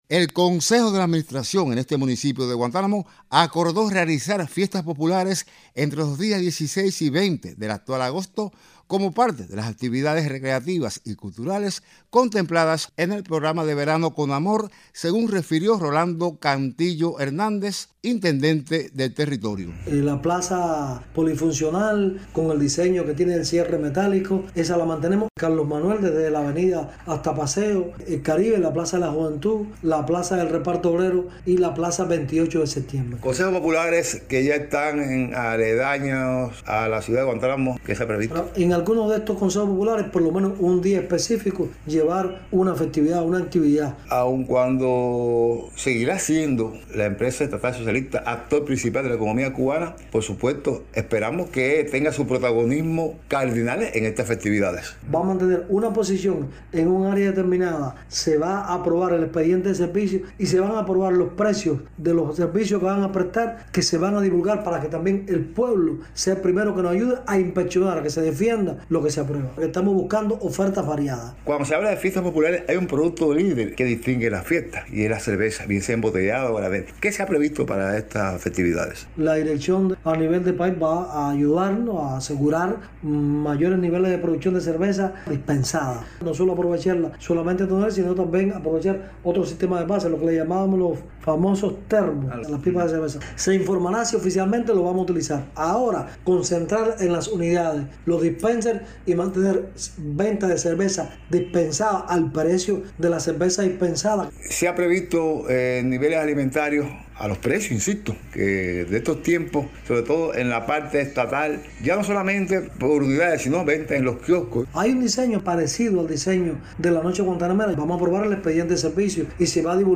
Entrevista-al-Intendente-Rolando-Cantillo.mp3